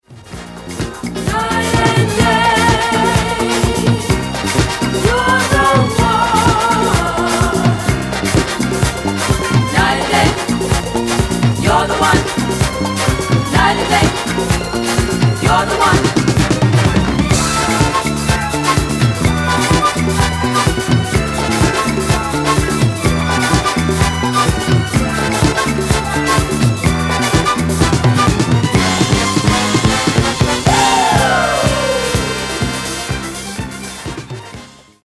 Genere:   Disco|Soul | Funky